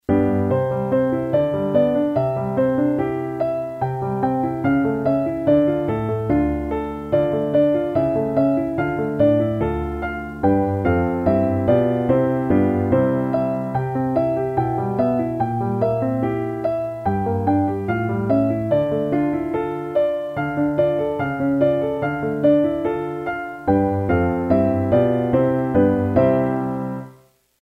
Duet for piano